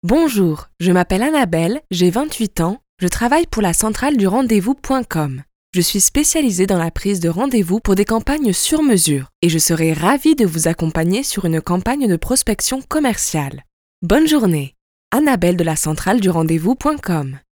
Nos agents sont des cadres parfaitement bilingues sans accent !
(Enregistrements audios réalisés avec du matériel studio)